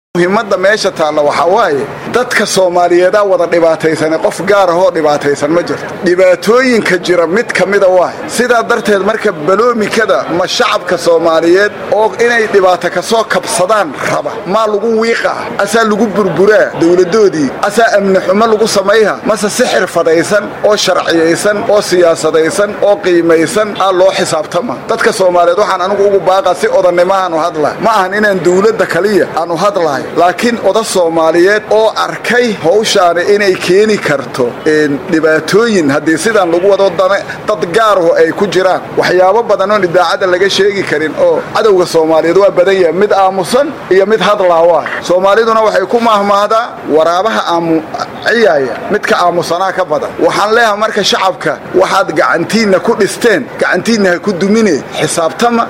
Somalidu waxaad moodaa in ayna welli xisaabtamin waa hadalkii siyaasi Salaad Cali Jeelle oo wax laga waydiiyay nin Itoobiya ku noqonayay oo dawlada Somaliya fududeysay laguna magacaabo C/kariin Qalbi dhagax.